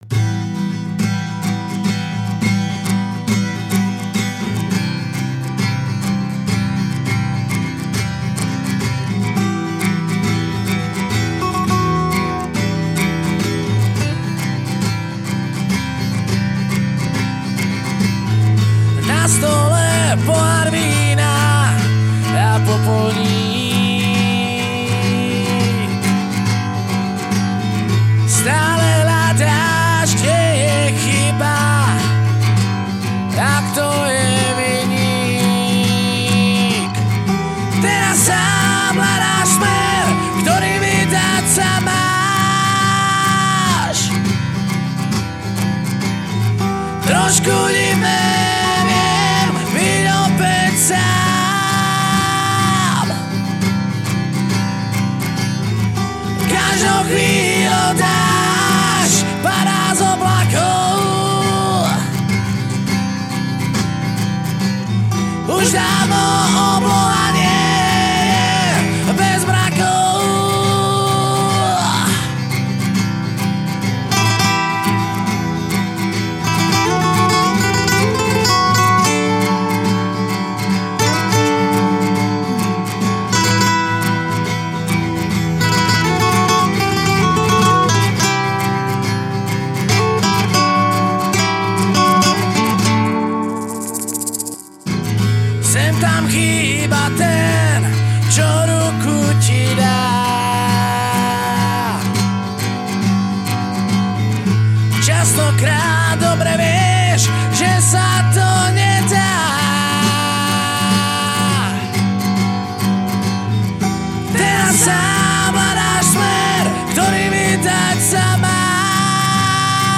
Žánr: Rock
Vocals, guitars
Bass guitar
Drums